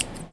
R - Foley 190.wav